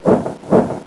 Heroes3_-_Griffin_-_MoveSound.ogg